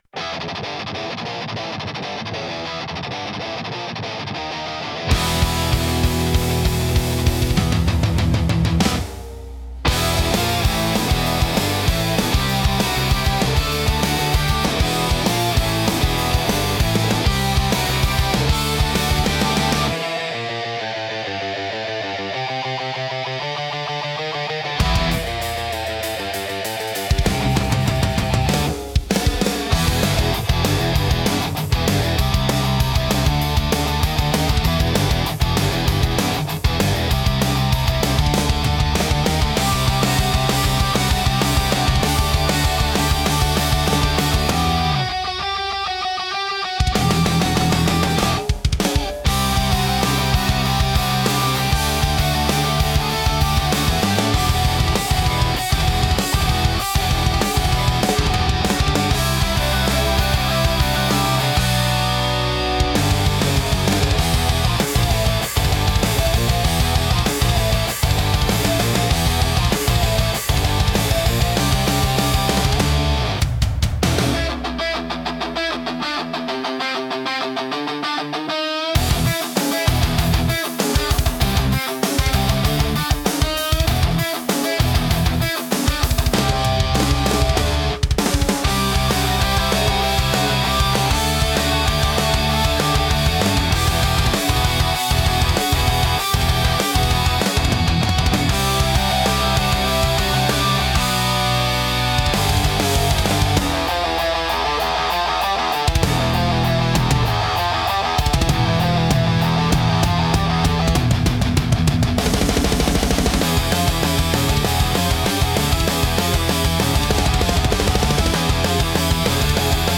High-Speed Rock